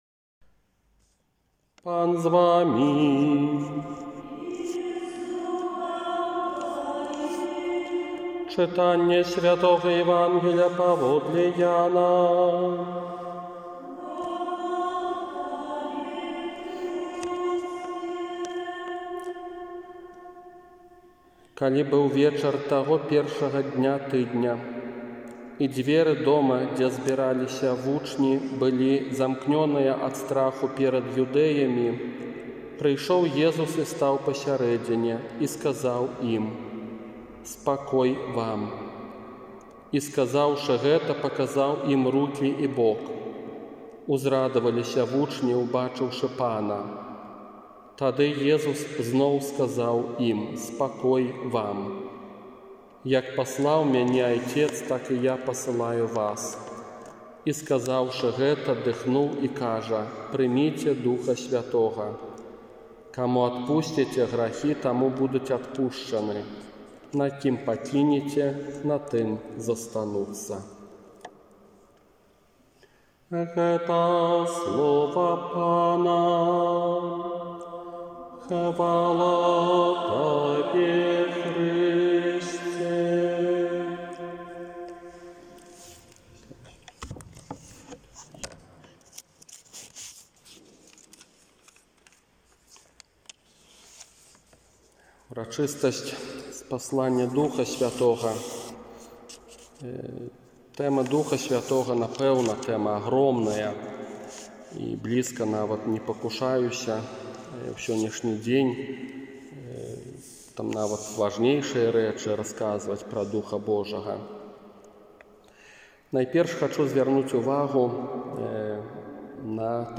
ОРША - ПАРАФІЯ СВЯТОГА ЯЗЭПА
Казанне на Ўрачыстасць Спаслання Духа Святога 31 мая 2020 года
Пяцiдзесятнiца_2020_казанне.m4a